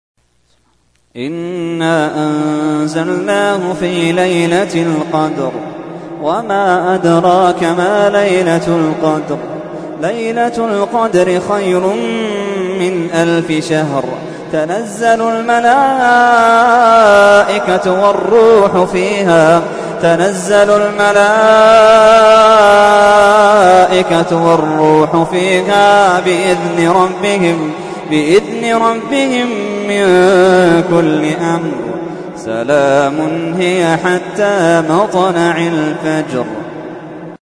تحميل : 97. سورة القدر / القارئ محمد اللحيدان / القرآن الكريم / موقع يا حسين